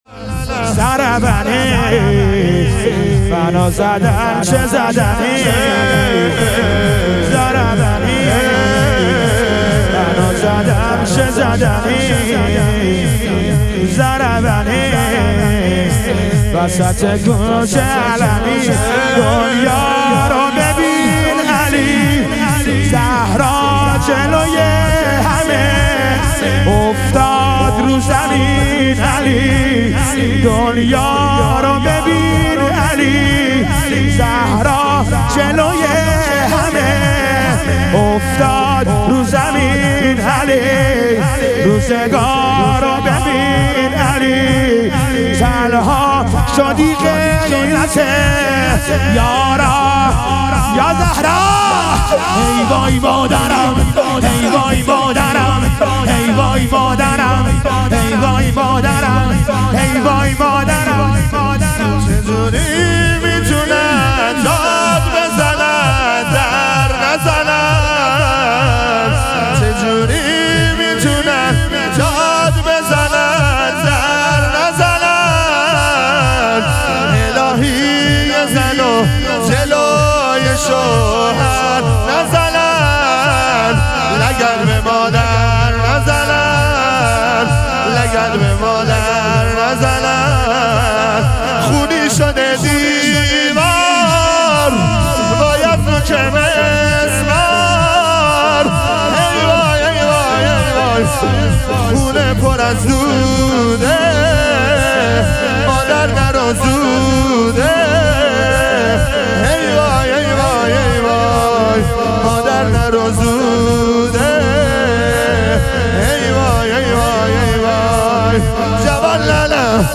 شام غریبان حضرت زهرا علیها سلام - لطمه زنی